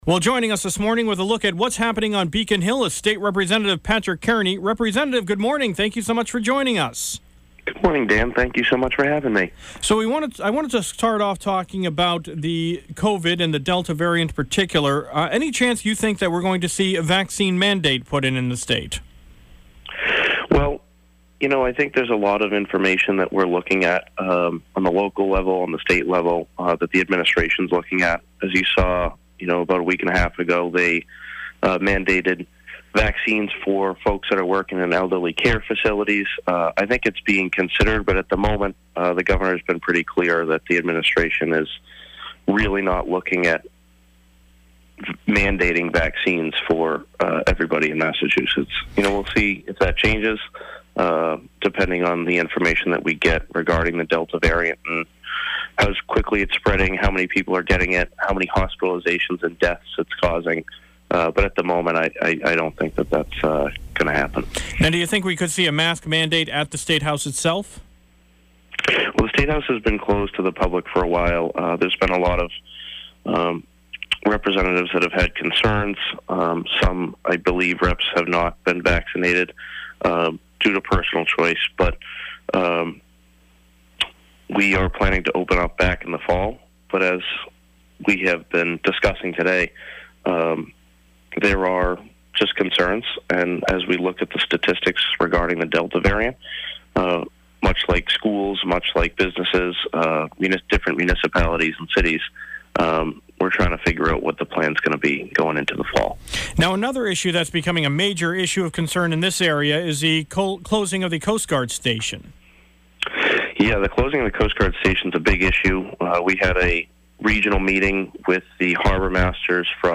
State Representative Patrick Kearney speaks